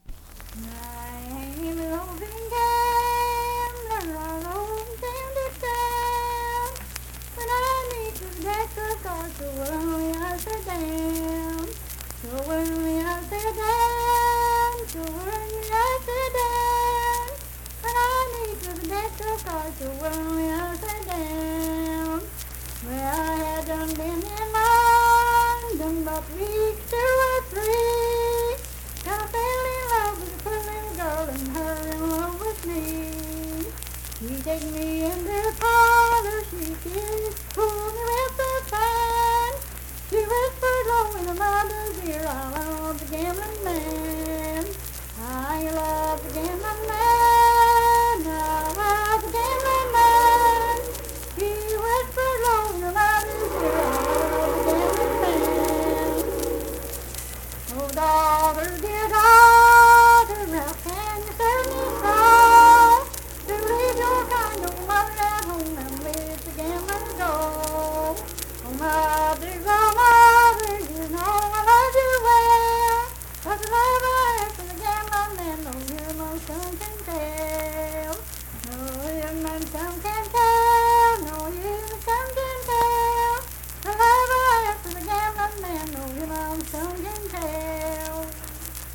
Unaccompanied vocal music performance
Verse-refrain 5d(4w/R).
Voice (sung)